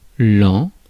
Ääntäminen
Synonyymit année printemps Ääntäminen France Tuntematon aksentti: IPA: [ɑ̃] Haettu sana löytyi näillä lähdekielillä: ranska Käännös Substantiivit 1. aasta Muut/tuntemattomat 2. kalendriaasta Suku: m .